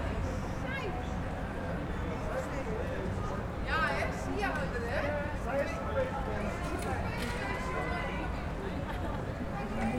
UrbanSounds
Environmental
Streetsounds
Noisepollution